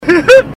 Laugh 28